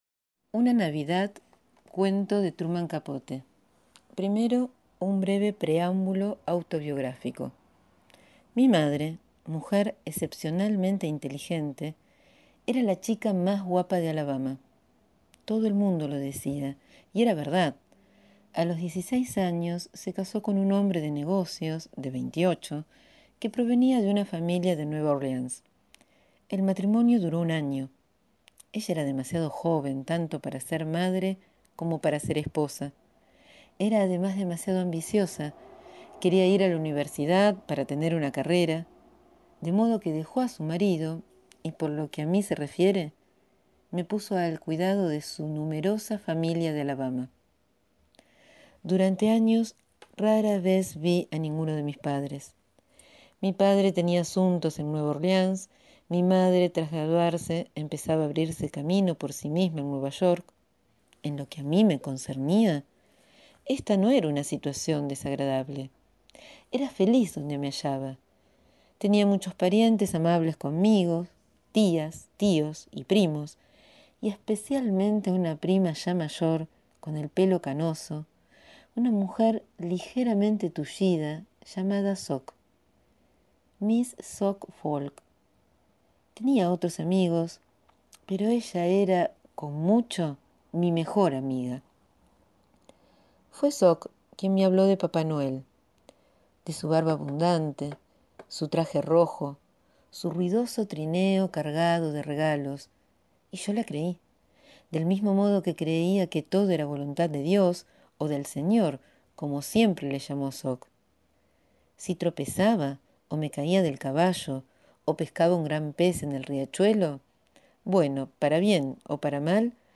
Quizá justamente por eso, hoy leo este relato autobiográfico de Truman Capote (EE.UU 1924-1984) publicado por primera vez en la revista Mademoiselle. Entre las idas y vueltas de una navidad, un niño de campo, allá por los años 30, palpa las vulnerabilidades de los adultos, sus carencias afectivas y los distintos costados de las creencias y la ficción.